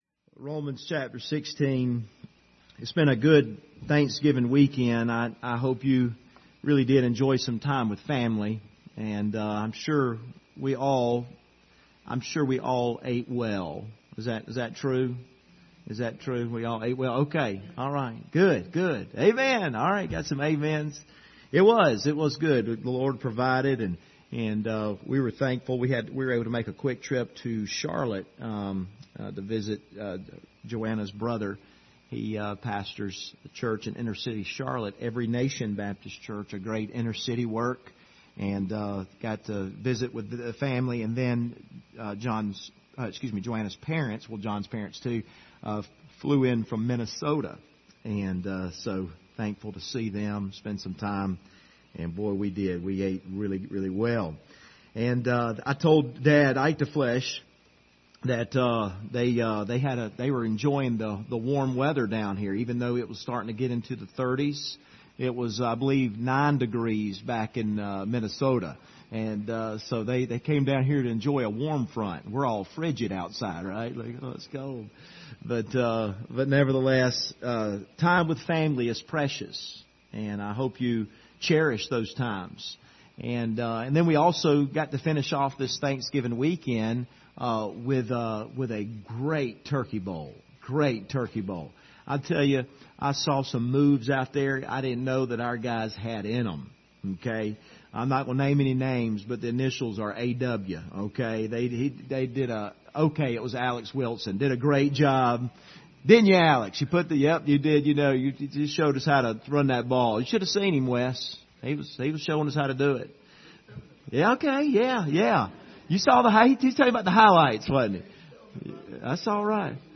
Passage: Romans 16:13 Service Type: Sunday Evening